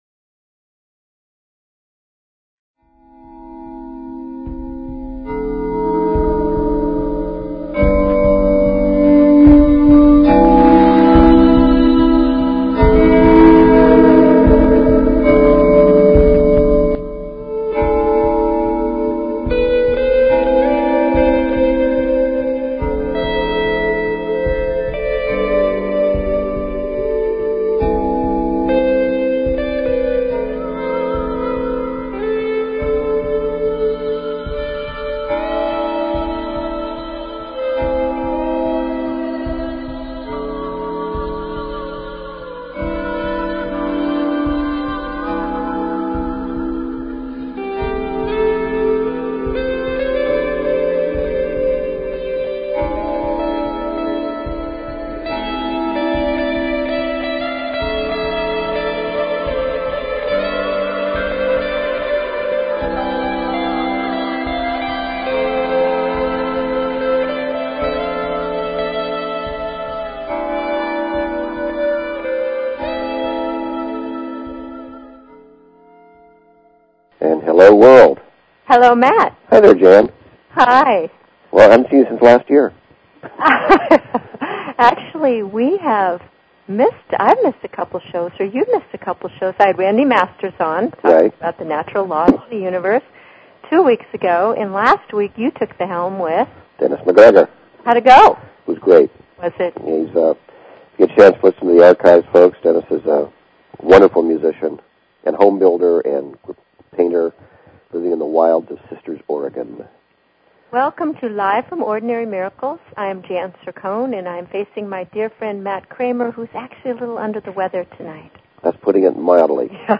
Talk Show Episode, Audio Podcast, Live_from_Ordinary_Miracles and Courtesy of BBS Radio on , show guests , about , categorized as
Join us and our guests, leaders in alternative Health Modalities and Sound Healing, every Wednesday evening at the Ordinary Miracles Store in Cotati,California. We love call in questions!!!